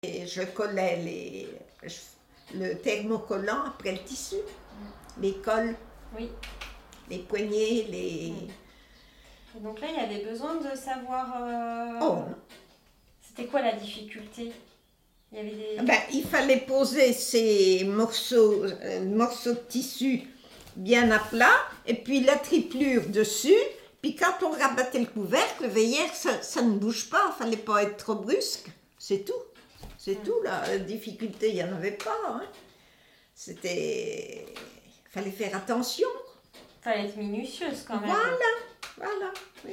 Figure 15 : Extrait vidéo du témoignage d’une ouvrière (thermocolleuse) en confection.
Cet article s’appuie sur une collecte audiovisuelle de la mémoire de l’industrie de la ville de Vierzon donnant lieu à la création d’un site Internet